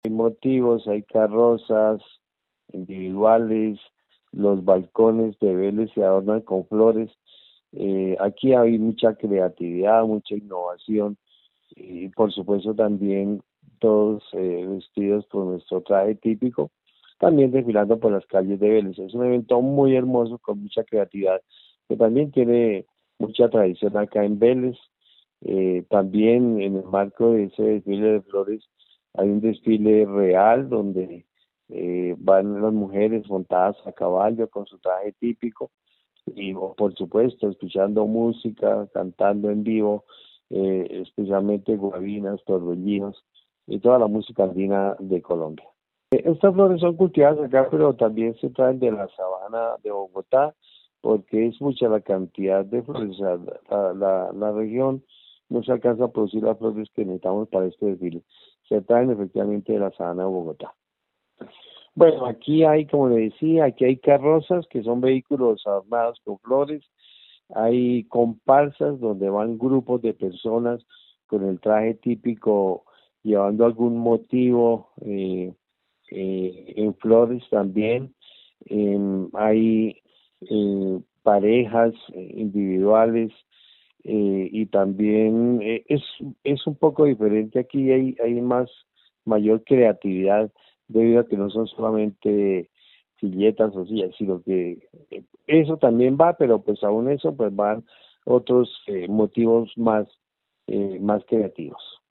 Orlando Ariza, alcalde de Vélez